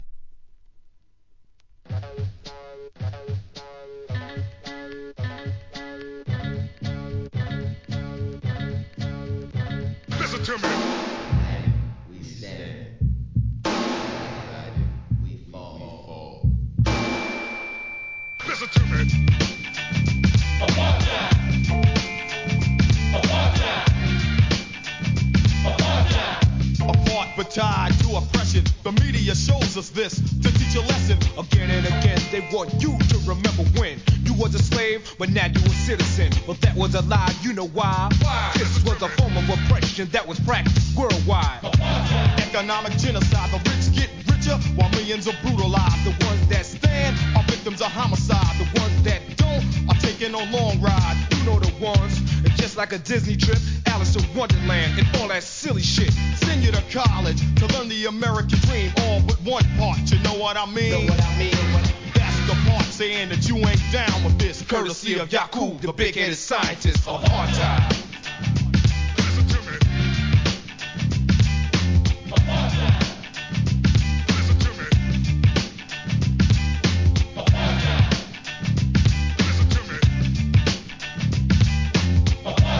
HIP HOP/R&B
この年代ならではのFUNKYプロダクション、NEW JACK SWING好きも要クリック!!